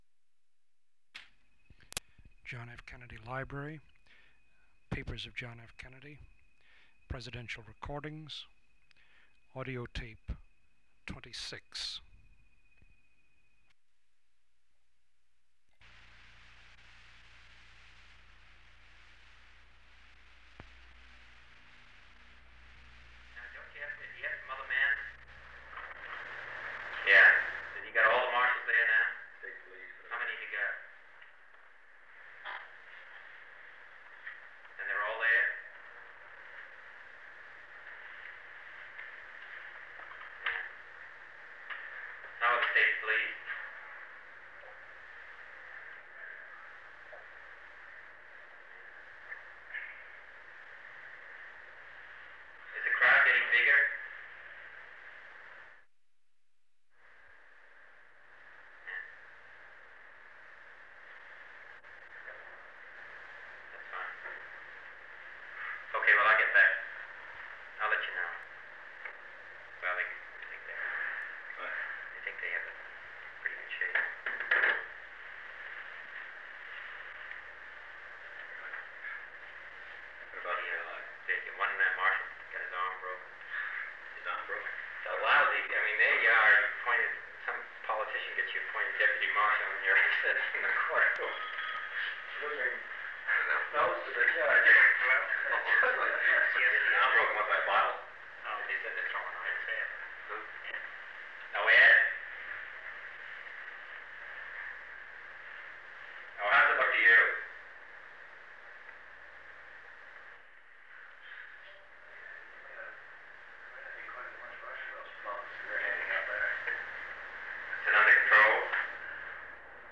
Secret White House Tapes | John F. Kennedy Presidency Meeting on Civil Rights Rewind 10 seconds Play/Pause Fast-forward 10 seconds 0:00 Download audio Previous Meetings: Tape 121/A57.